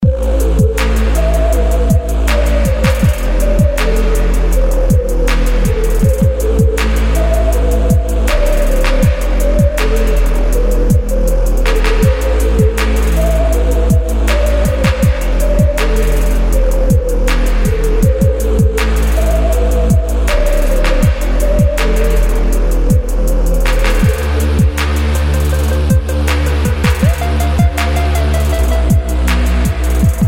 • Качество: 128, Stereo
Инструментальная